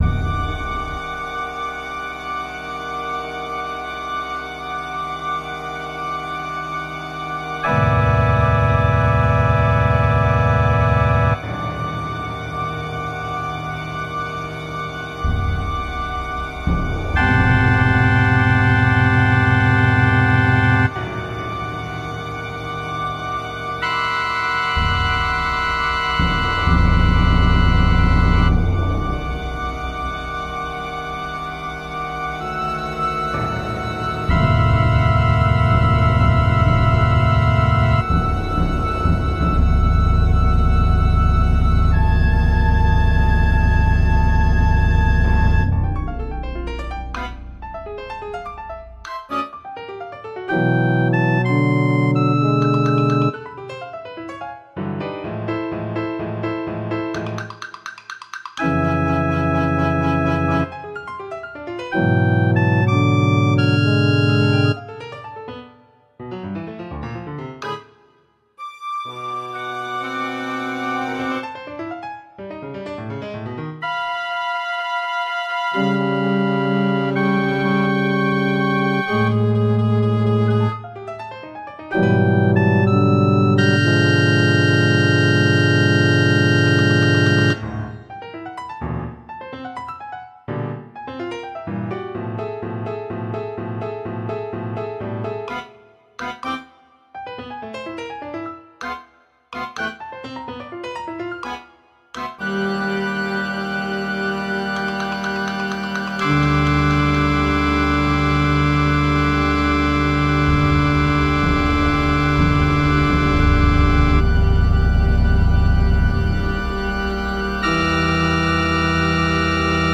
mp3 (computer generated)